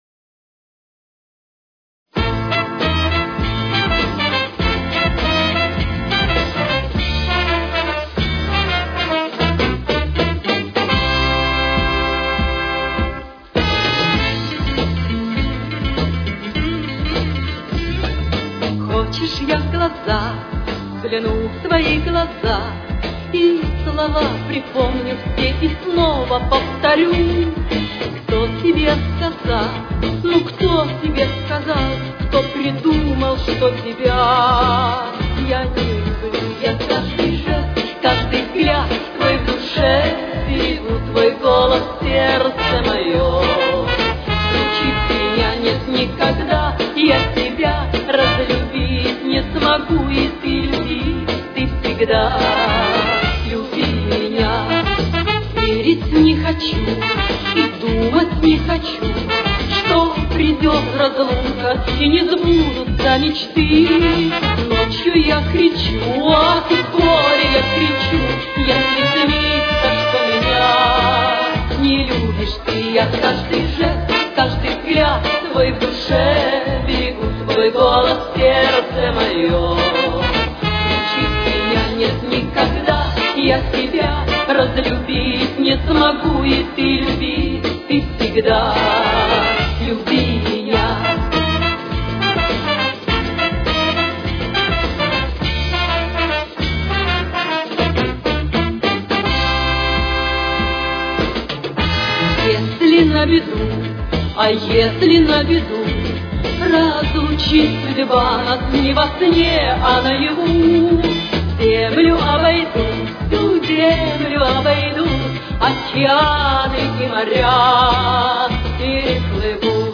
Темп: 108.